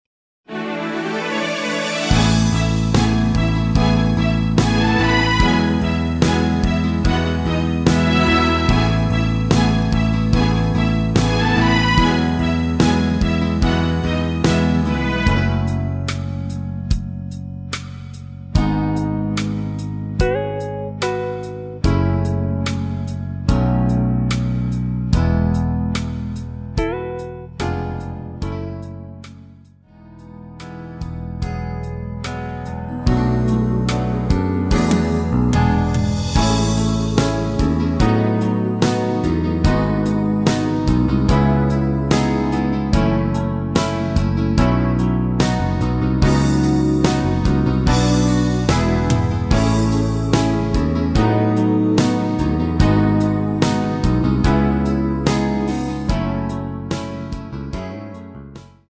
내린 MR 입니다.